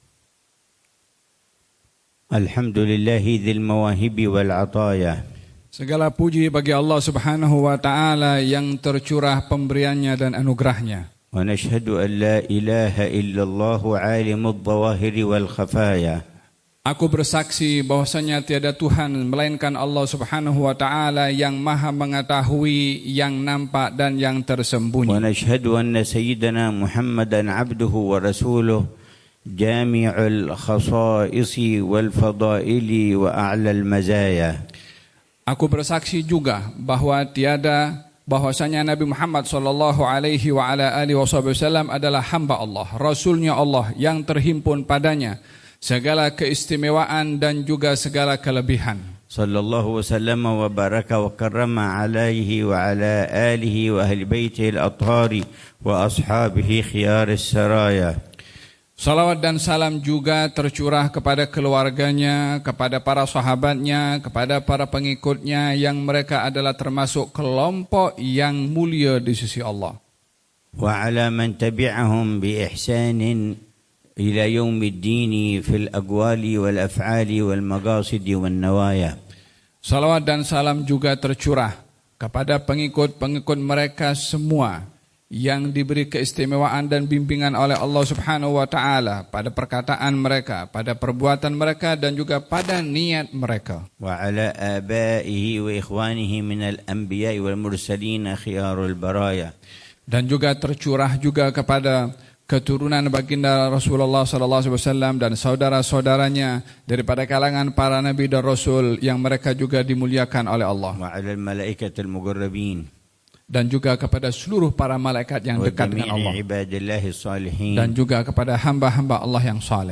محاضرة العلامة الحبيب عمر بن حفيظ في ملتقى العلماء، في ولاية جالا، تايلاند، الأربعاء 30 ربيع الثاني 1447هـ بعنوان: